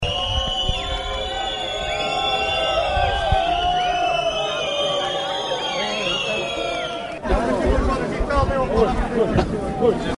A Ministra da Agricultura, Maria do Céu Antunes, foi recebida esta segunda-feira em Macedo de Cavaleiros por cerca de uma centena de agricultores em frente à Câmara Municipal de Macedo de Cavaleiros.
À sua partida, recebeu assobios: